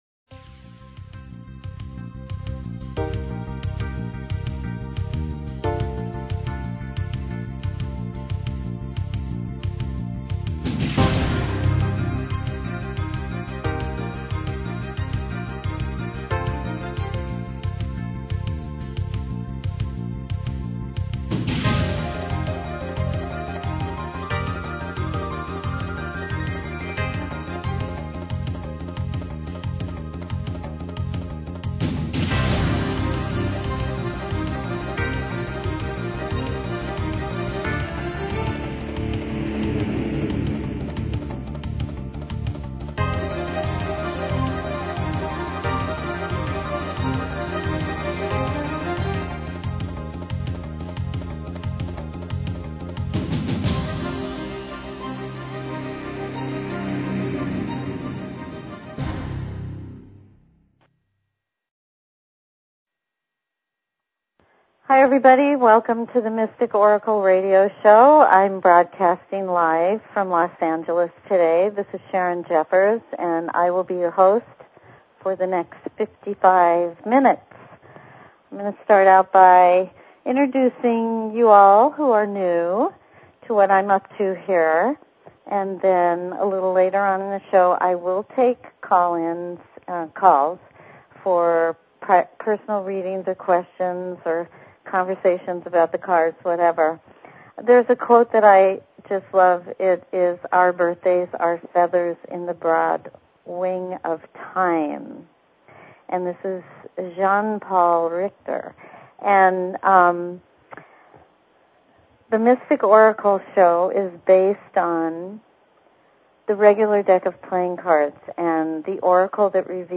Talk Show Episode, Audio Podcast, The_Mystic_Oracle and Courtesy of BBS Radio on , show guests , about , categorized as
Discover the secrets hidden in your birthday, your relationship connections, and your life path. Open lines for calls.